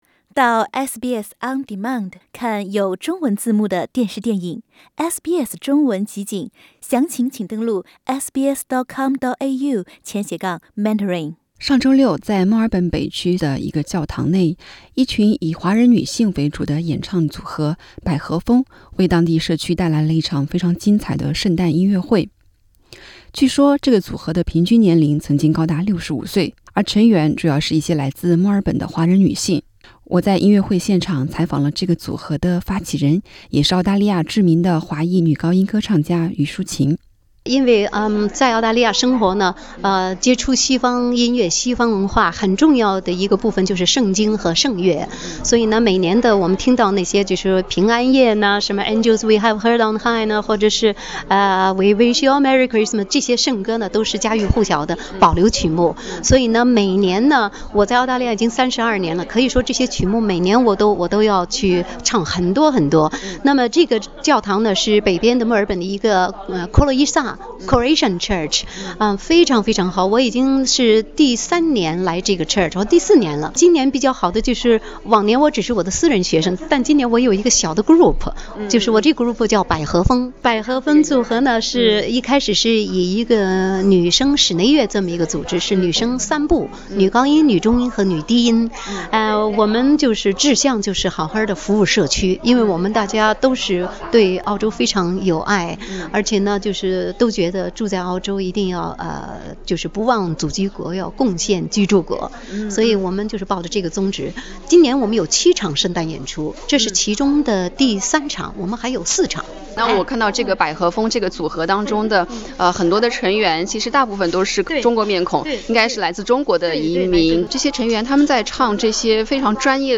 完整内容点击上方图片收听采访。